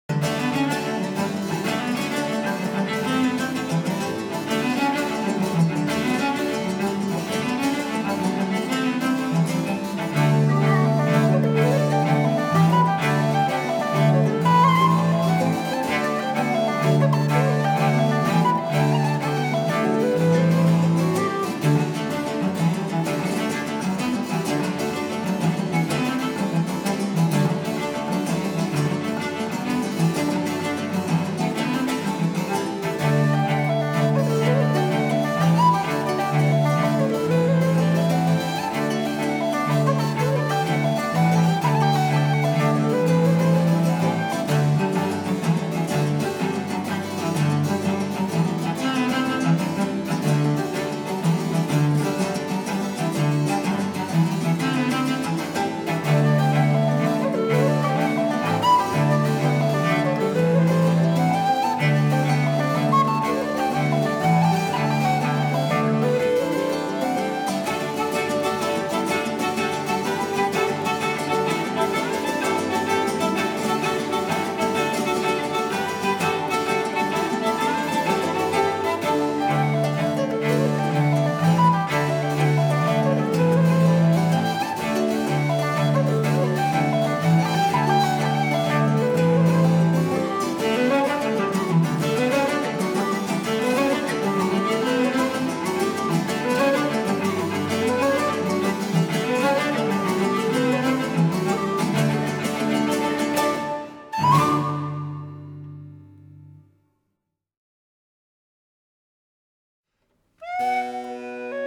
Susato Whistles
Cello
Harpsichord
Baroque and Classical guitar